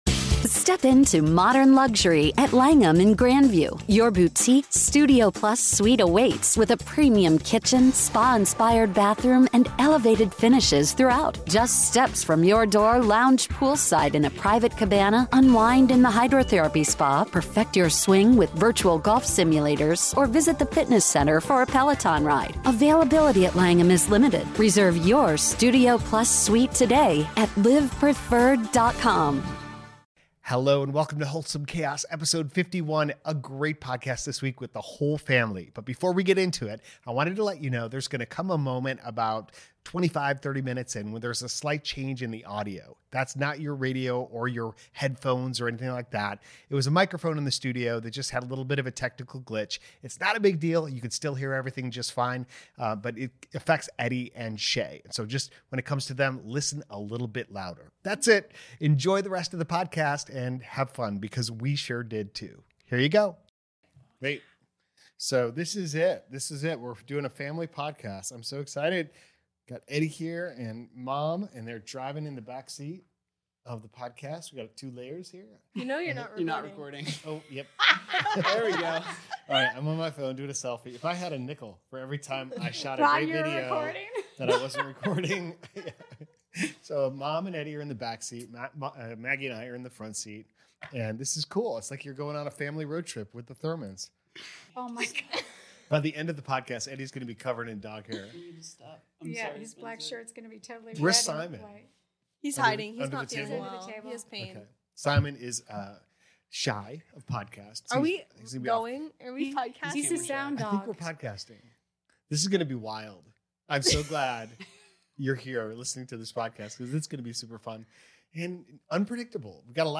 The whole family in Hot-lanta studio, (which gets considerably warmer throughout the podcast)! Fun, free flowing conversation about “next chapters,” neurodiversity, recording content in public, and taking control of sleep dreams!